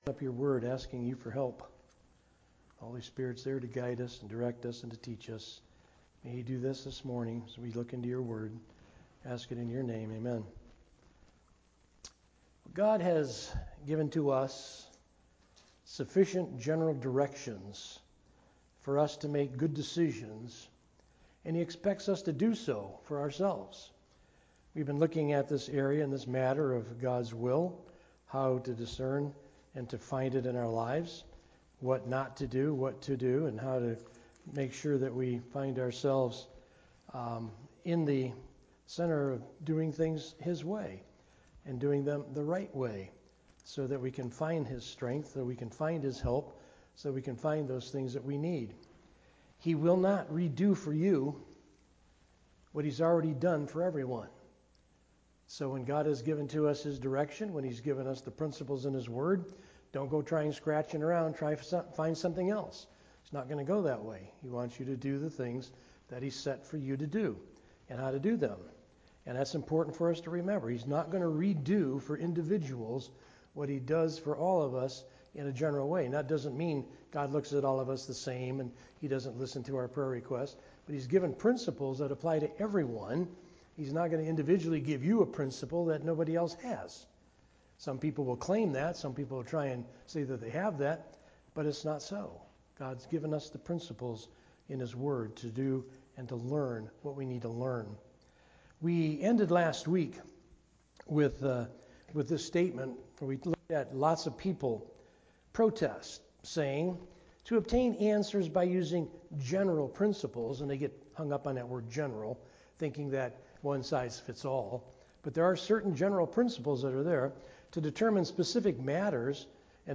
A message from the series "Sunday Morning - 11:00."
Sermon